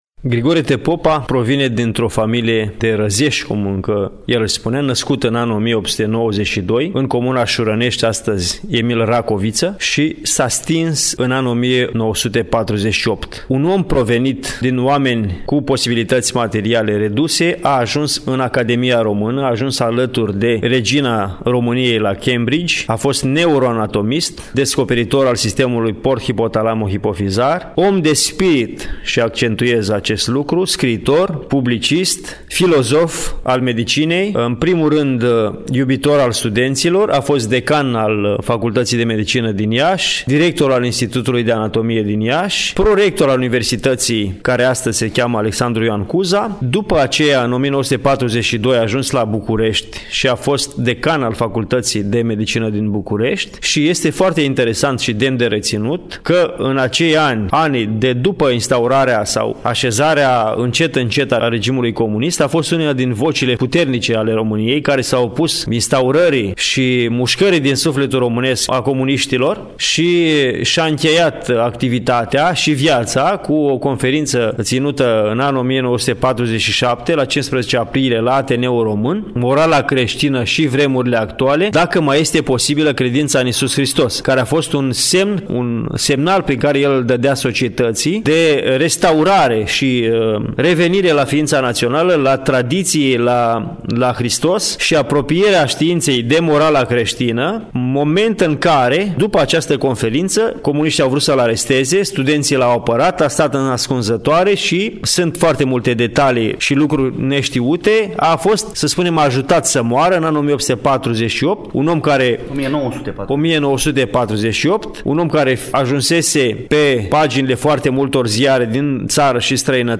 Pășim în incinta Muzeul de Istorie a Medicinei din cadrul Universității de Medicină și Farmacie „Gr. T. Popa” Iași.